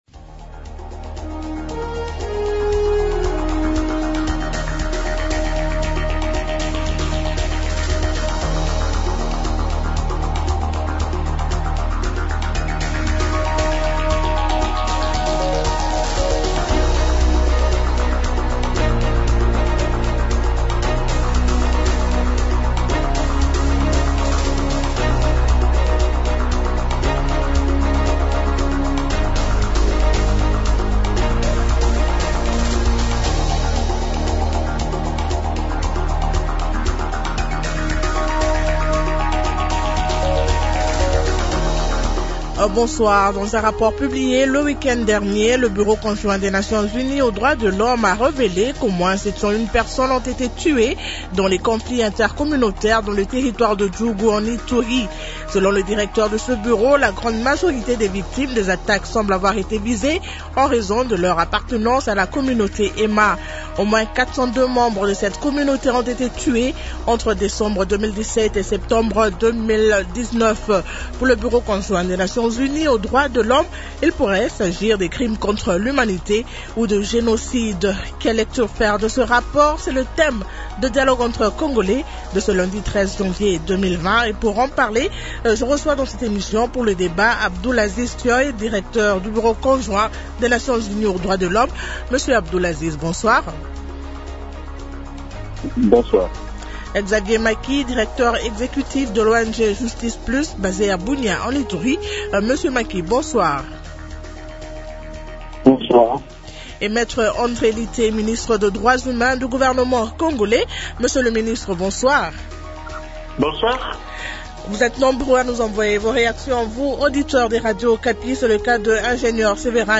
-Me André Lite, Ministre des droits humains du gouvernement congolais.